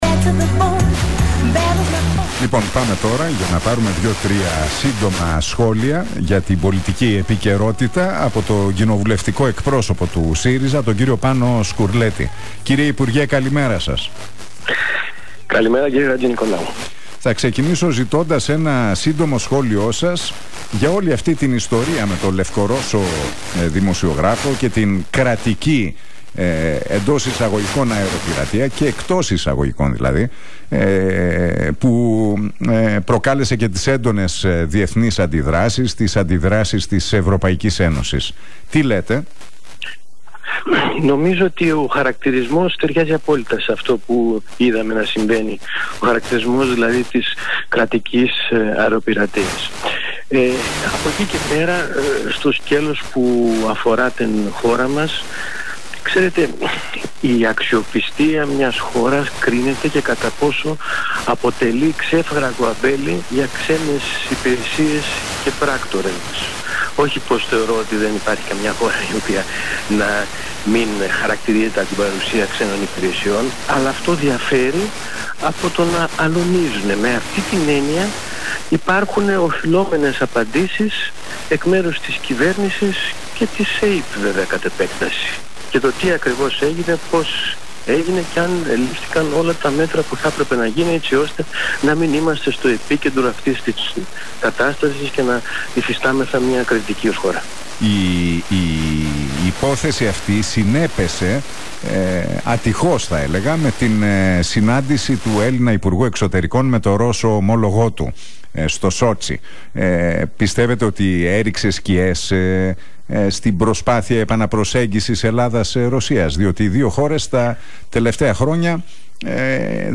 Ο κοινοβουλευτικός εκπρόσωπος του ΣΥΡΙΖΑ, Πάνος Σκουρλέτης, μιλώντας στον Realfm 97,8 και στην εκπομπή του Νίκου Χατζηνικολάου...